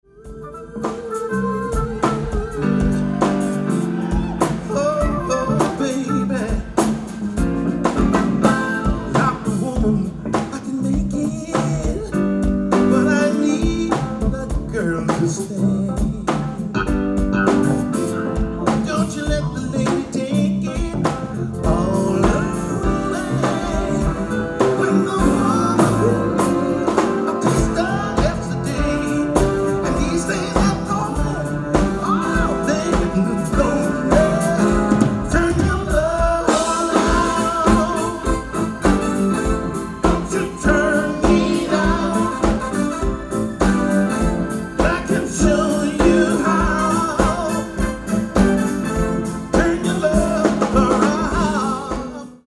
ライブ・アット・ケズィック・シアター、フィラデルフィア 10/23/2011
※試聴用に実際より音質を落としています。